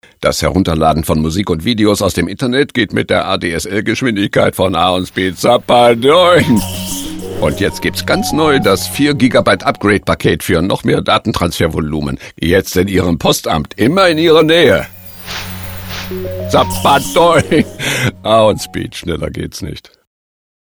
Unterstützung fand die TV-Kampagne durch Hörfunk-Spots mit der markanten Stimme von Jürgen Thormann.